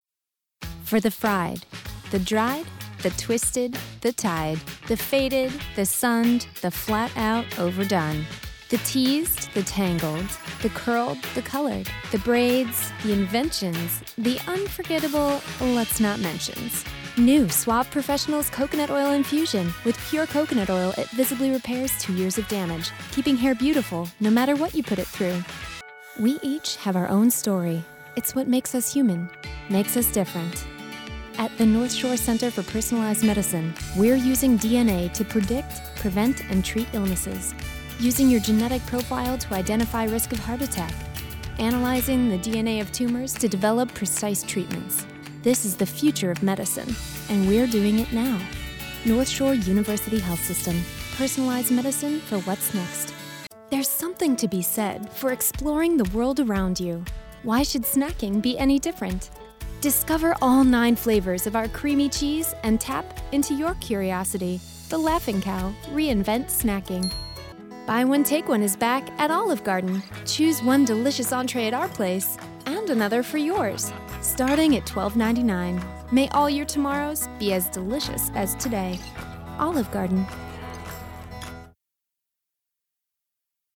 Chicago : Voiceover : Commercial : Women